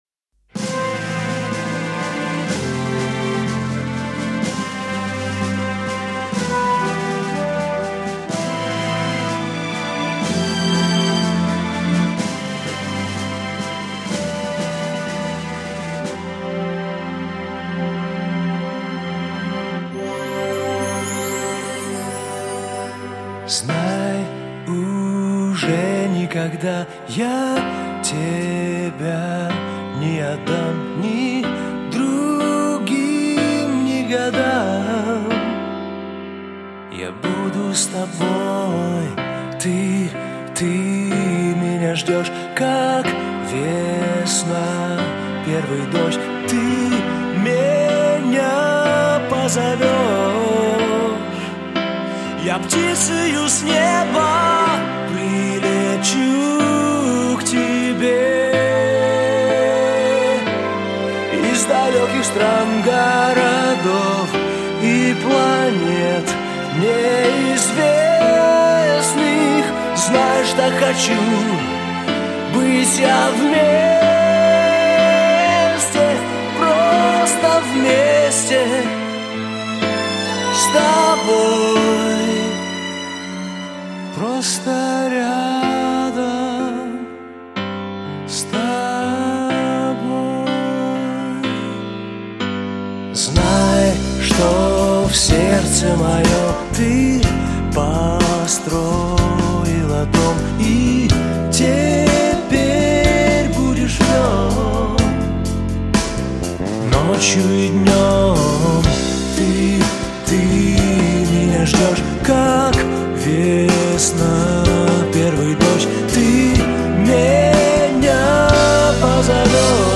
1086 просмотров 904 прослушивания 84 скачивания BPM: 124